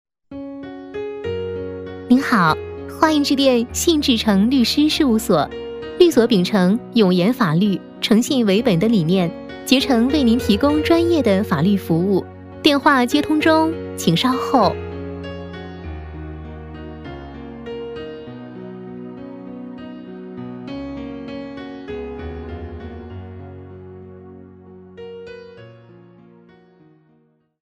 定制彩铃-女22-温婉甜美-律师事务所.mp3